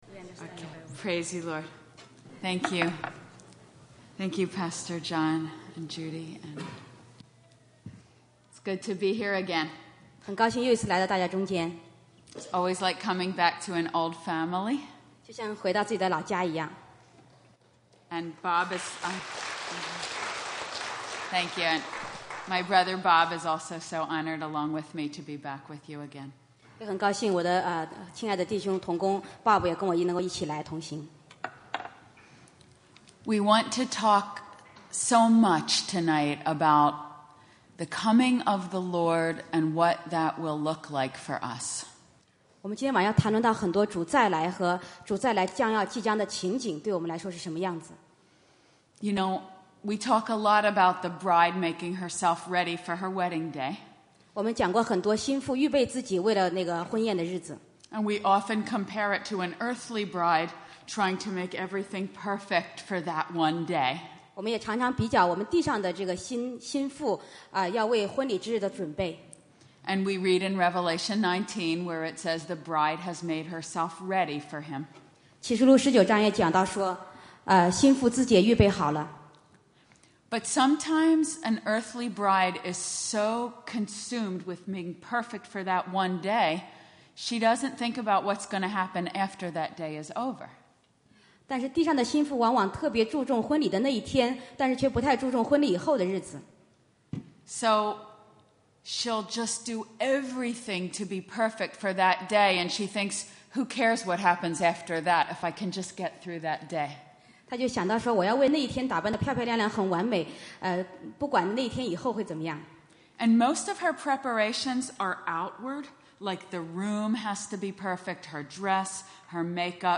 成为基督新妇培灵会（一）